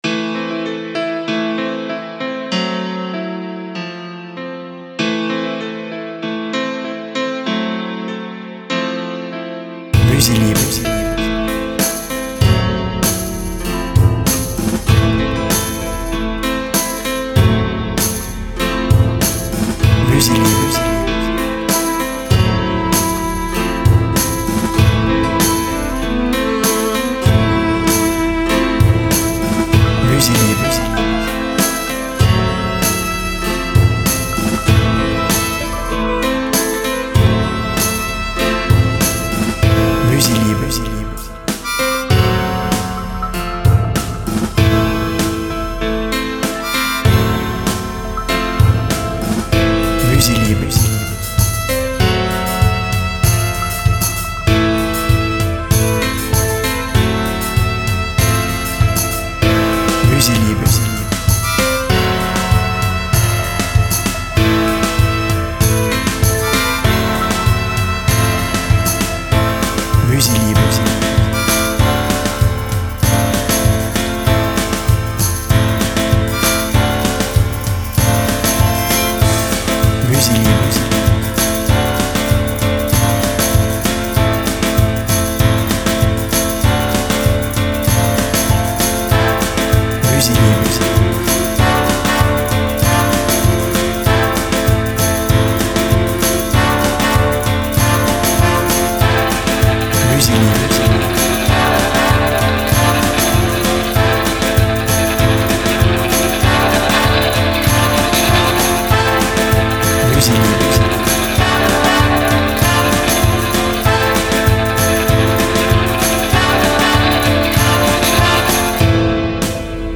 Cette chanson épique et moderne vous fera voyager
BPM Moyen